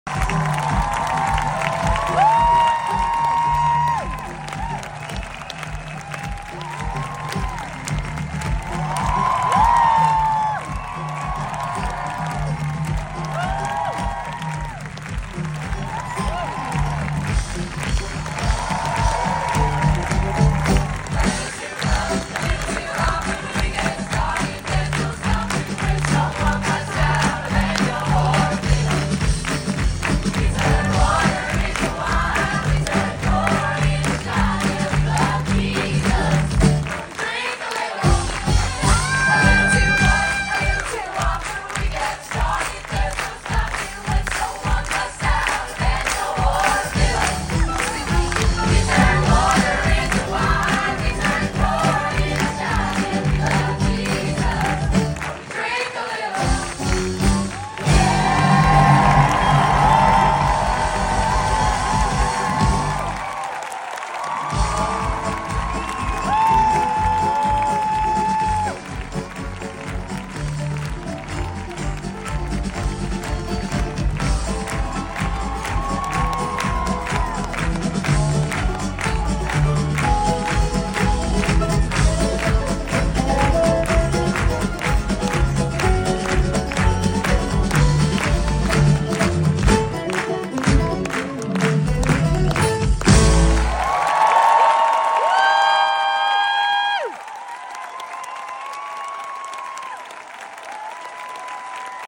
Regent's Park Open Air Theatre